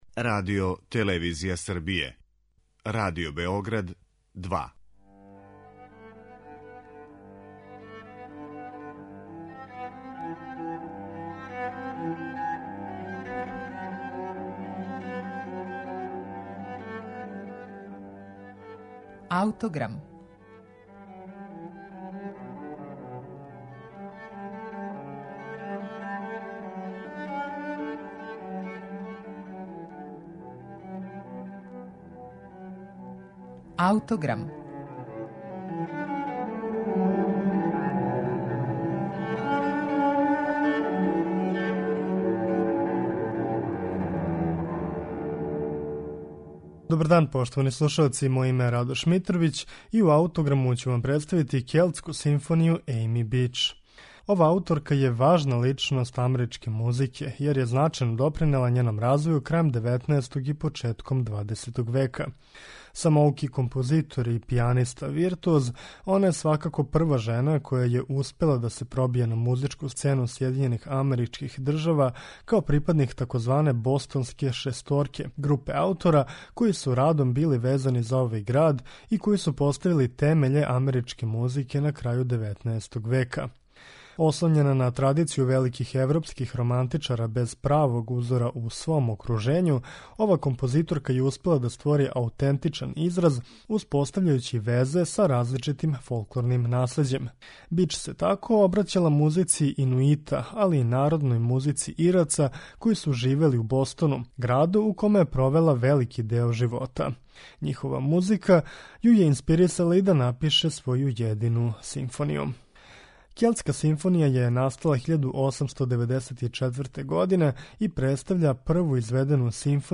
Келтску симфонију Ејми Бич, слушаћете у извођењу Симфонијског оркестра Детроита и Нема Јарвија.